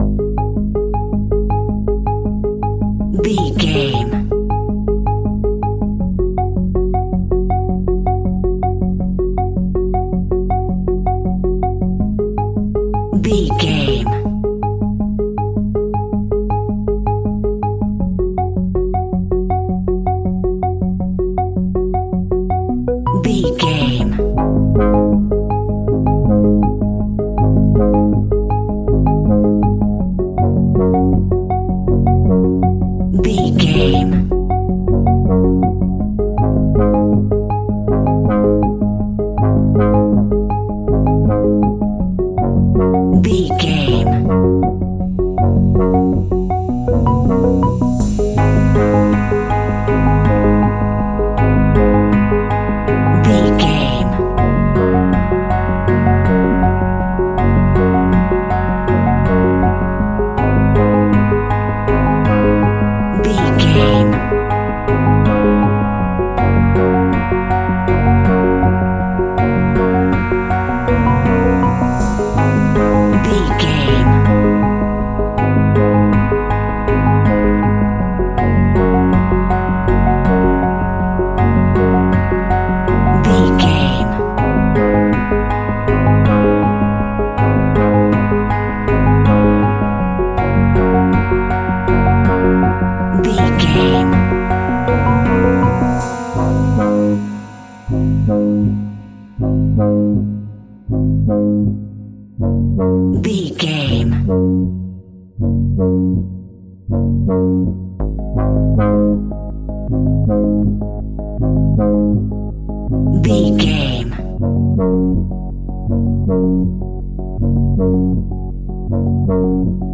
Aeolian/Minor
G#
Slow
ominous
dark
eerie
synthesiser
instrumentals
Horror Pads
Horror Synths